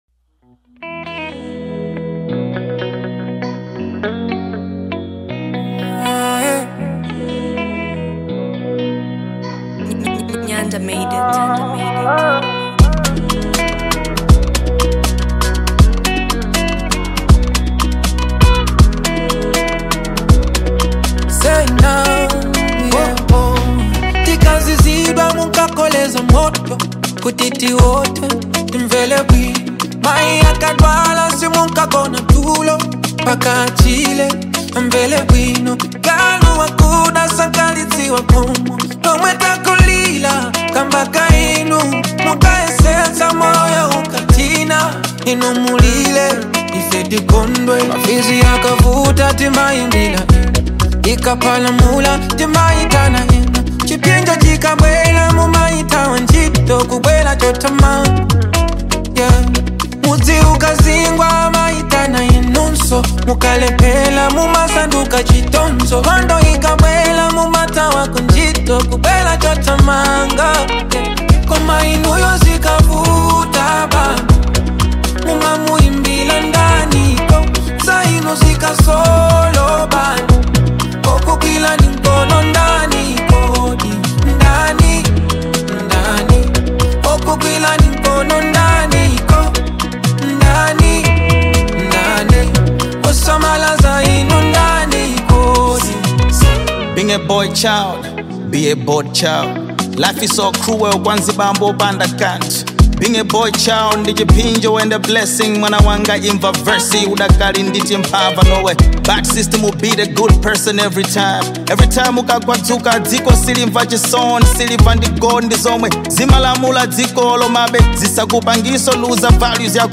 With its infectious beat and captivating vocals